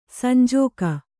♪ sanjōga